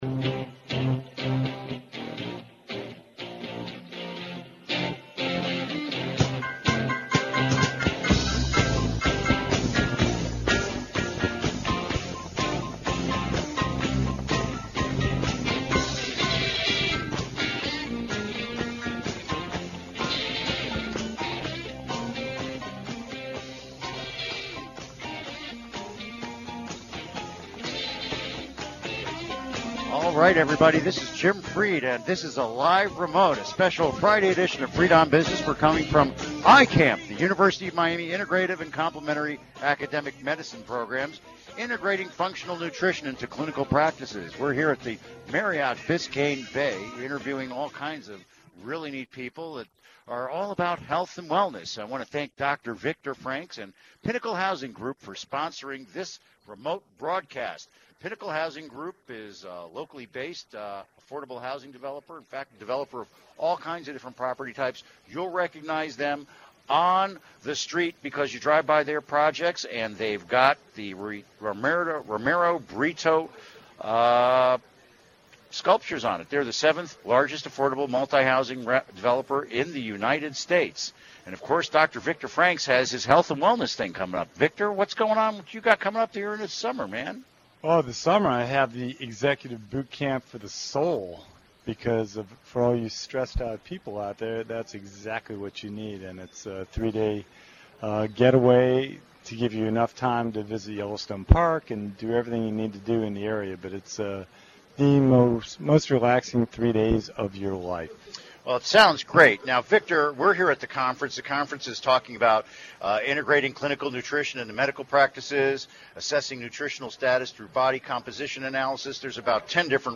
LIVE REMOTE from UHealth's Integrating Functional Nutrition Into Clinical Practice Confrence